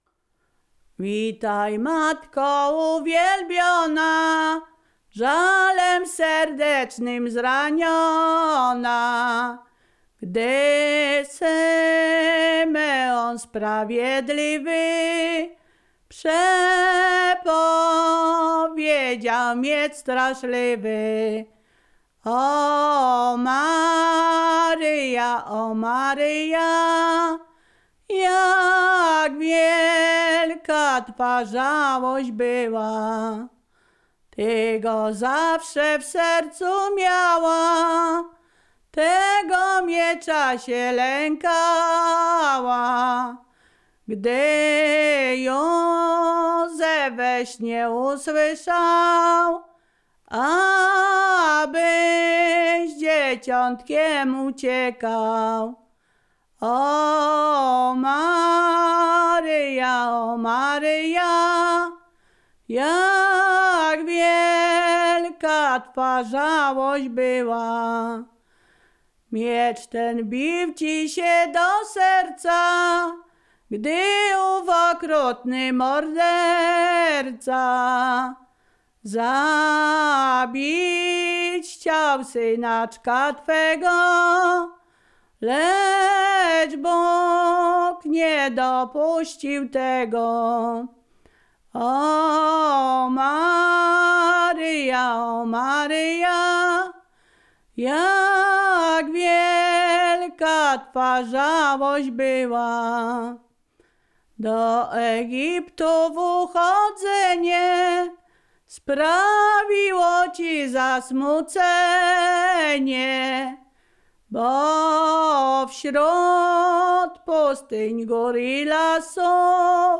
Ziemia Radomska
nabożne maryjne